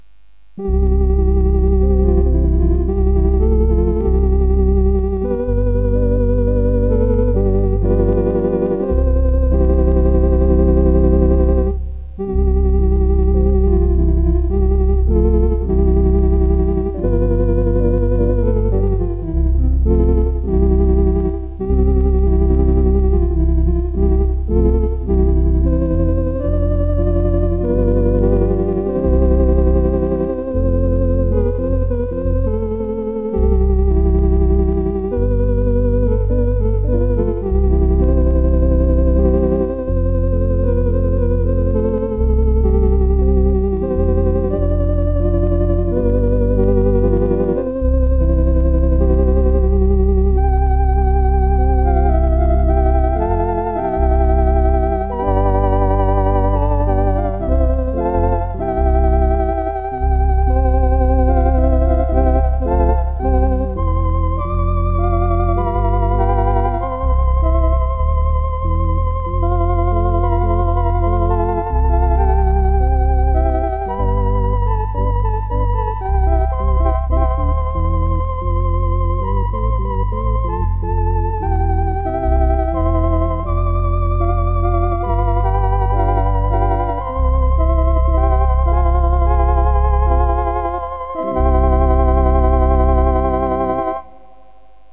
Description:Traditional Hymn
Organ version - MP3 file @ 192Kbps